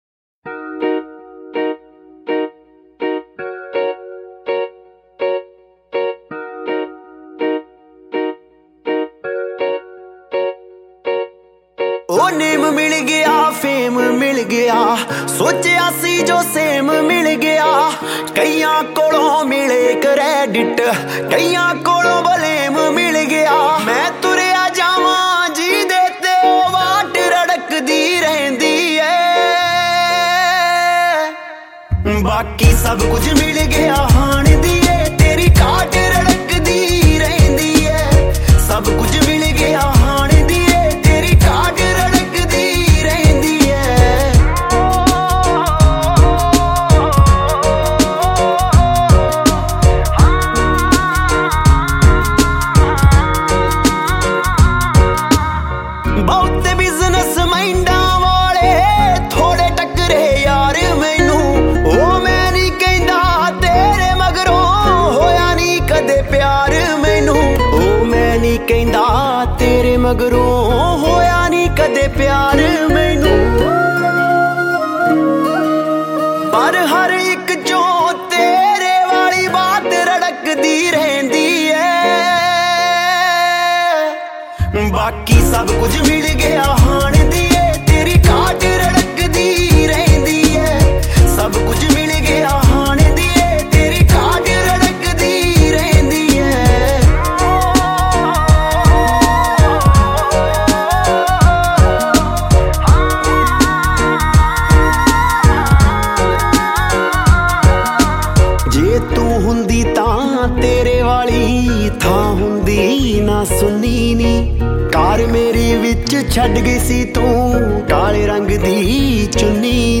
2020 Punjabi Mp3 Songs
Indian Pop